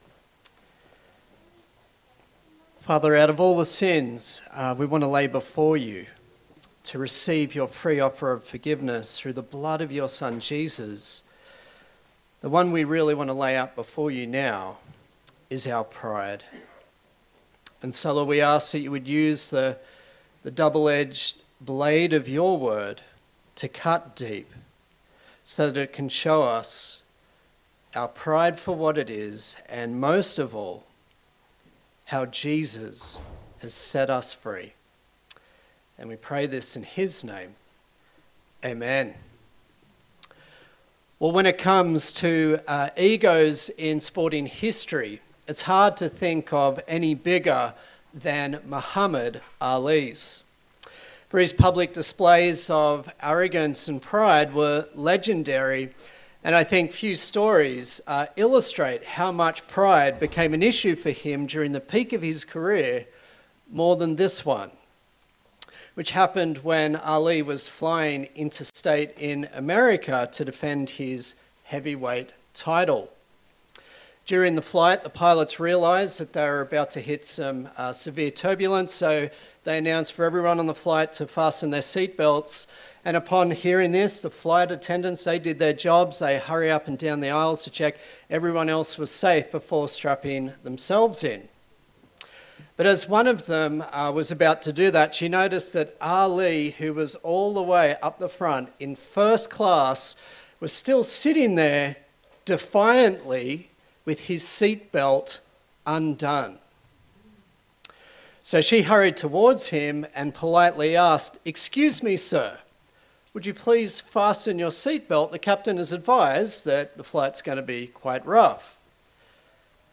Service Type: Sunday Service A sermon in the series on the book of Daniel